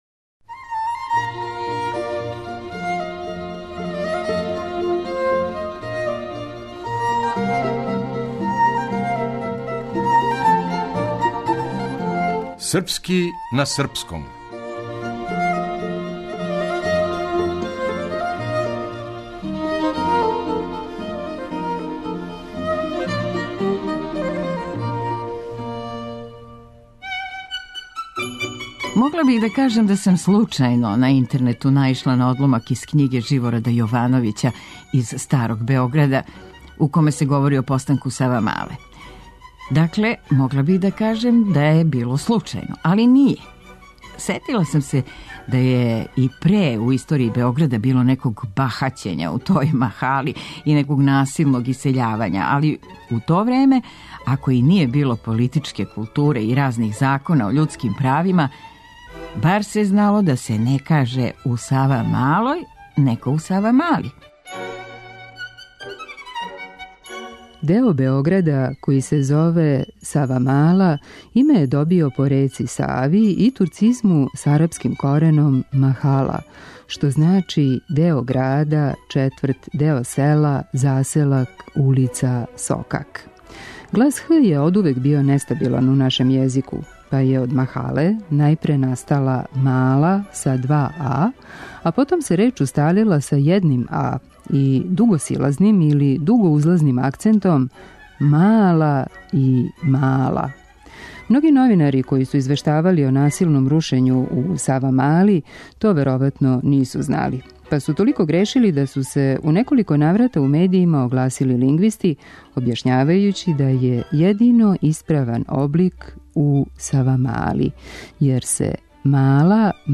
Дрaмскa умeтницa